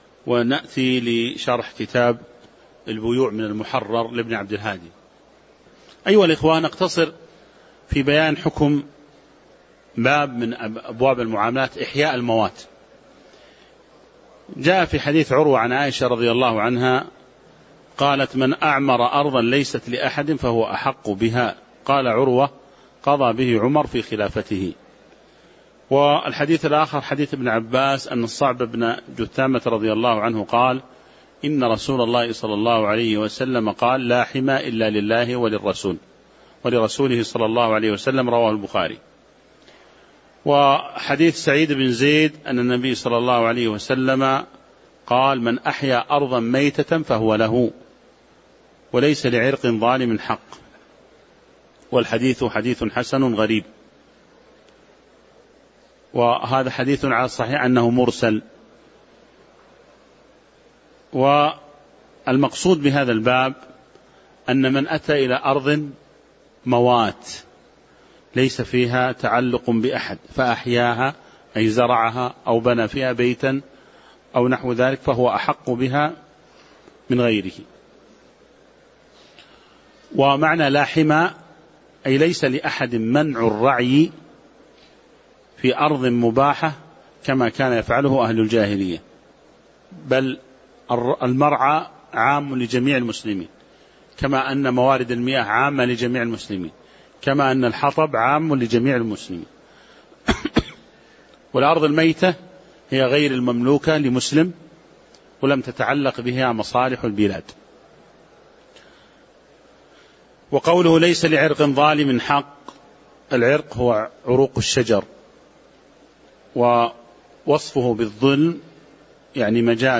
تاريخ النشر ١٩ ذو الحجة ١٤٤٦ هـ المكان: المسجد النبوي الشيخ